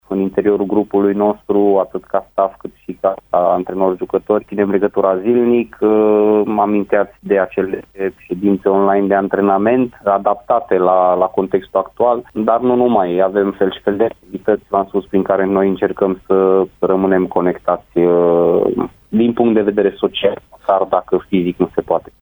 Cei doi tehnicieni, invitați azi la Arena Radio, au vorbit și despre stările de spirit ale loturilor pe care le conduc de la distanță: